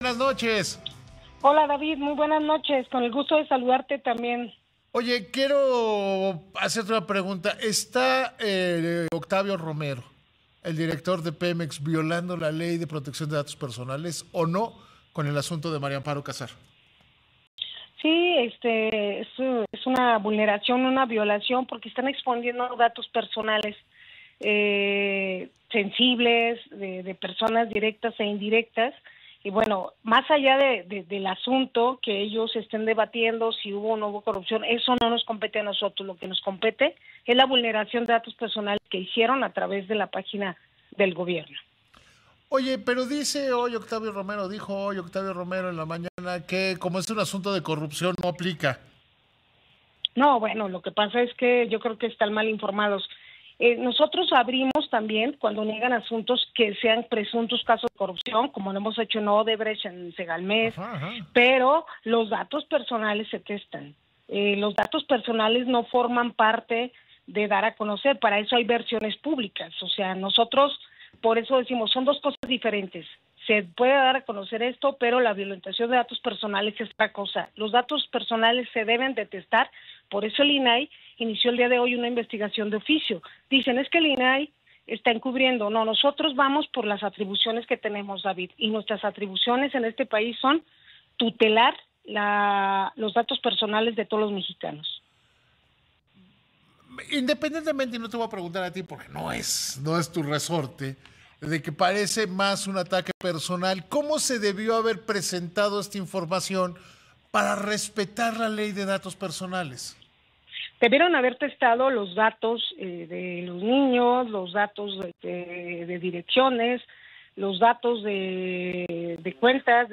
Entrevista con David Páramo - julietadelrio
Ahora hablo con David Páramo en Imagen Radio para hablar de la investigación de oficio que inicia el INAI ante presuntas violaciones a la privacidad de las personas por parte de las instituciones.